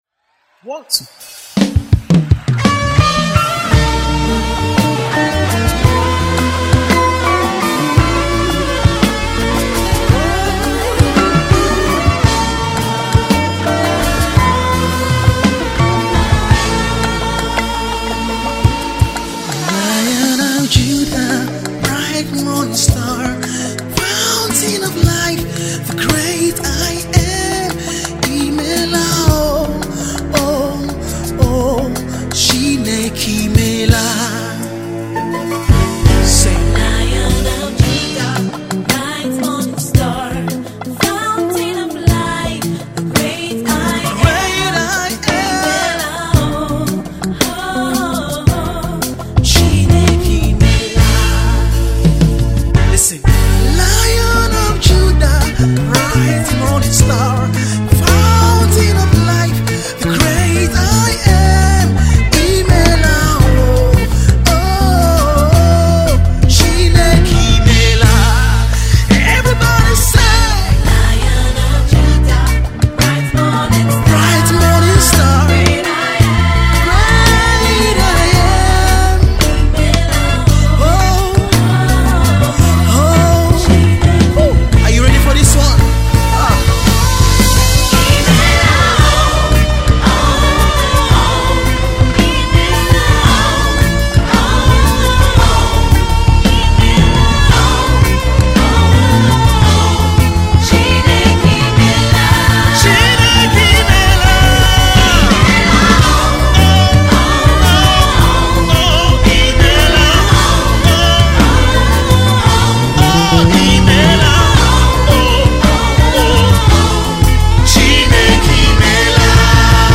Gospel
churns out new music of worship to God